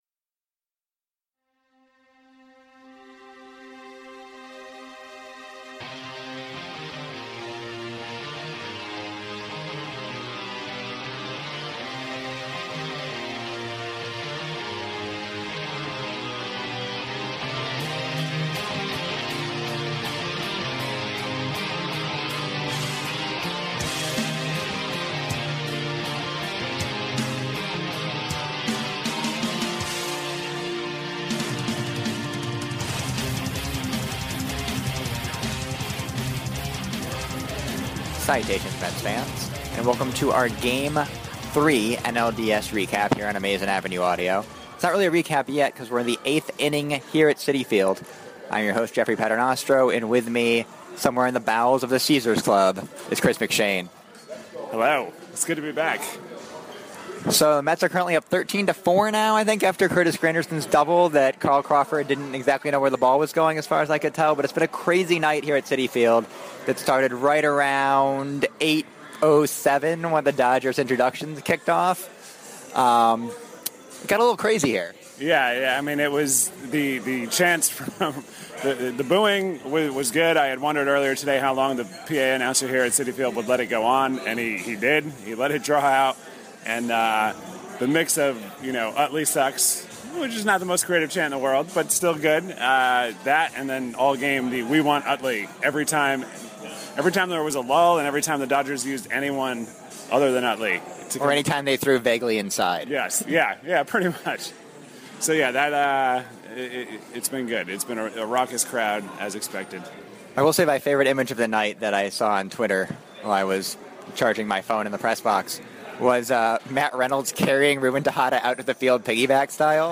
We also get serenaded by exuberant (and possibly inebriated) Mets fans belting out Bily Joel.